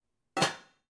دانلود افکت صدای بستن درب قابلمه ۲
افکت صدای بستن درب قابلمه 2 یک گزینه عالی برای هر پروژه ای است که به صداهای صنعتی و جنبه های دیگر مانند دیگ پخت و پز، درب بزرگ و فولادی ضد زنگ نیاز دارد.
Sample rate 16-Bit Stereo, 44.1 kHz
Looped No